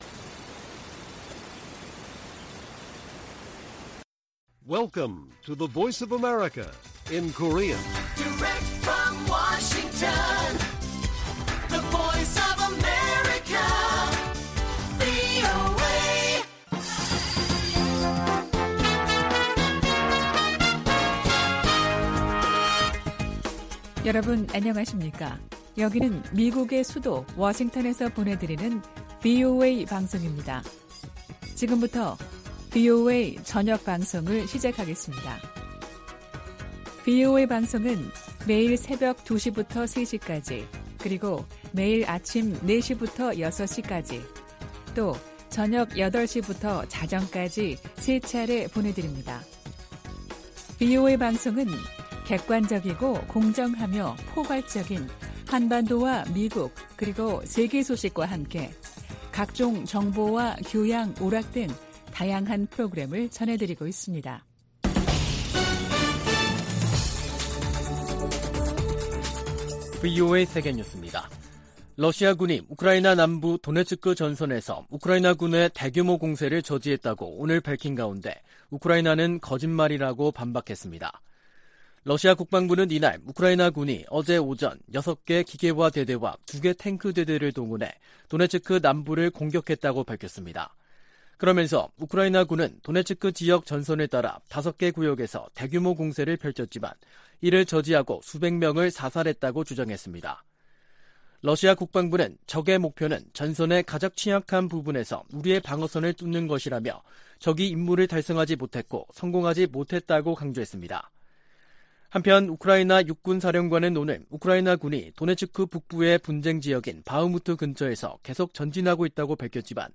VOA 한국어 간판 뉴스 프로그램 '뉴스 투데이', 2023년 6월 5일 1부 방송입니다. 북한 서해위성발사장에서 로켓 장착용 조립 건물이 발사패드 중심부로 이동했습니다. 김여정 북한 노동당 부부장은 유엔 안보리가 군사정찰위성 발사를 단독 안건으로 논의한 데 대해 비난했습니다. 안보리가 북한의 위성 발사에 관한 긴급 공개회의를 개최했지만 공식 대응에는 합의하지 못했습니다.